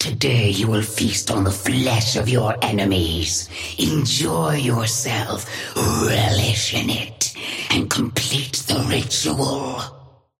Sapphire Flame voice line - Today you will feast on the flesh of your enemies.
Patron_female_ally_wrecker_start_05.mp3